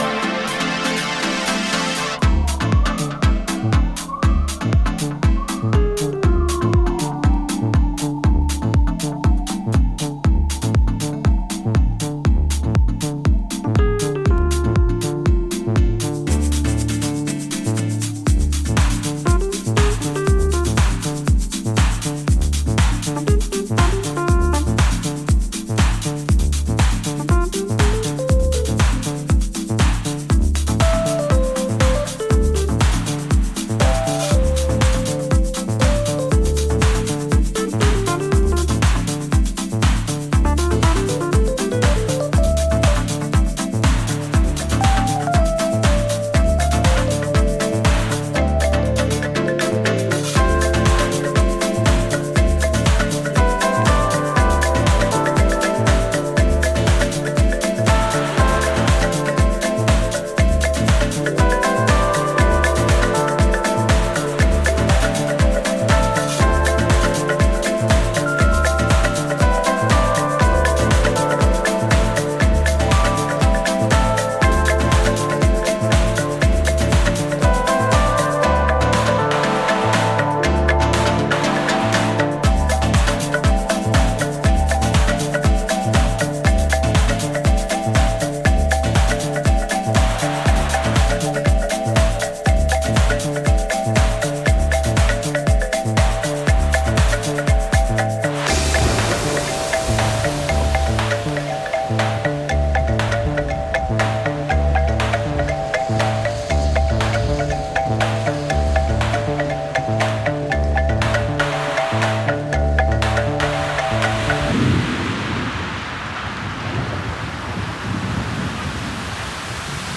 エレピをメインに据えたジャズファンク/フュージョン感のある